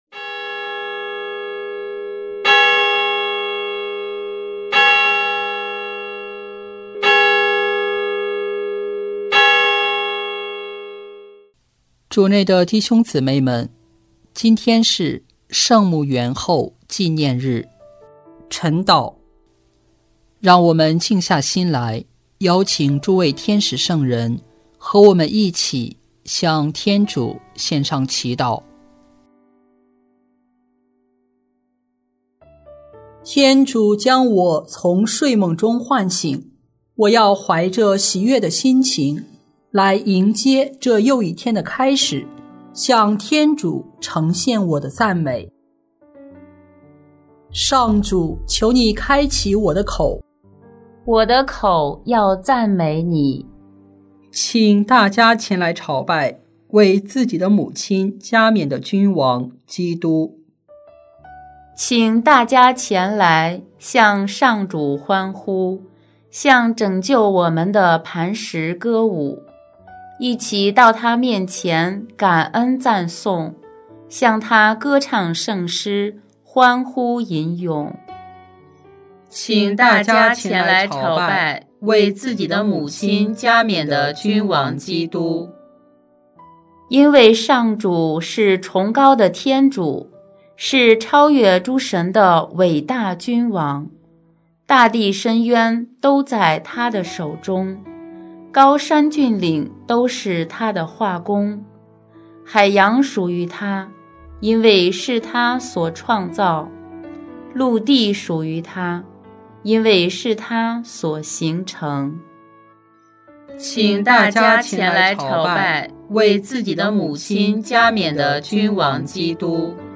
【每日礼赞】|8月22日圣母元后纪念日晨祷